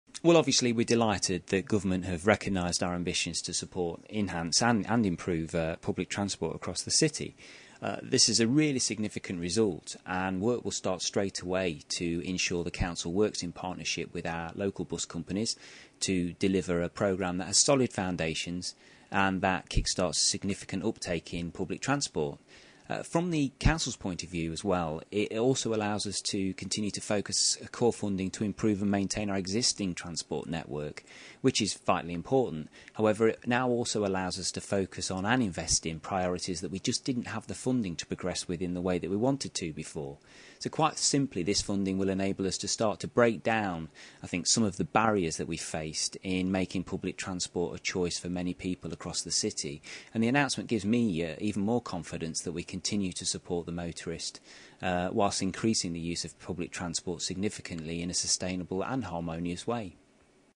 Cllr Matthew Holmes, Cabinet Member for Planning & Environment, Derby City Council reacts to the succesful funding bid for £2million from government to improve and promote local bus services.